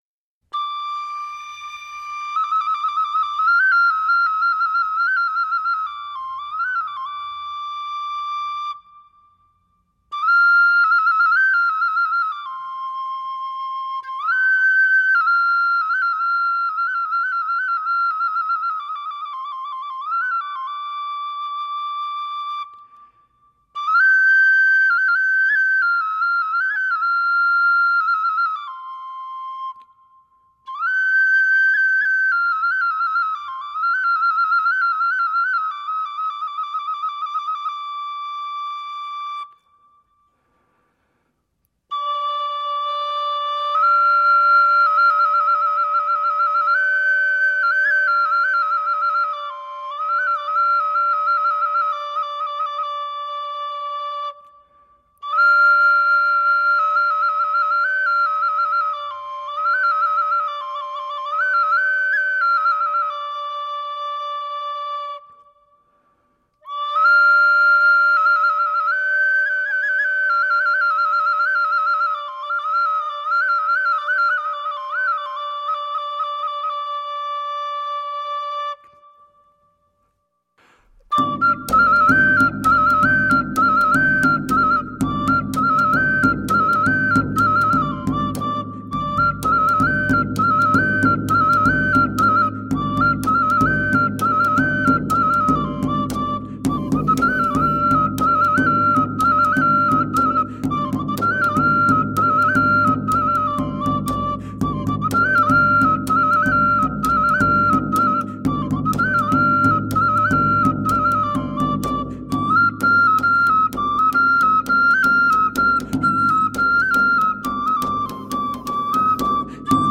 Medieval and middle eastern music.
Tagged as: World, Medieval, Arabic influenced